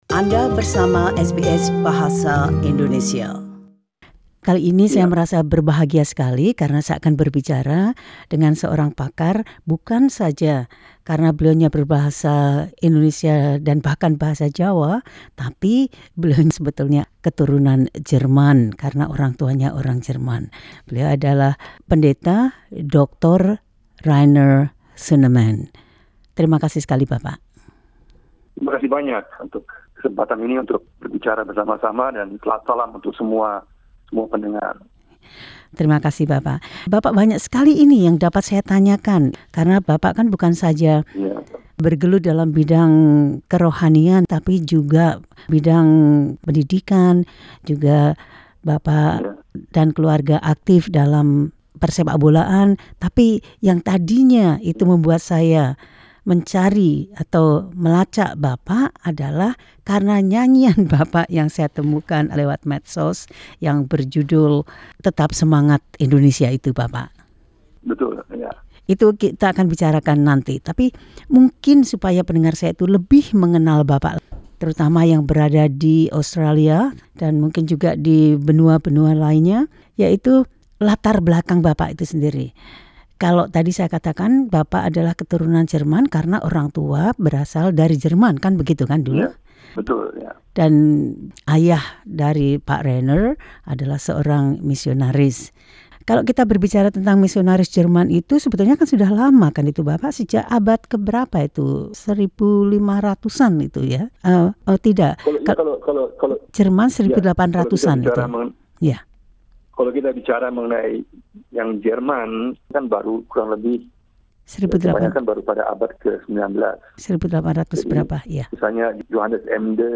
Dalam wawancara tersebut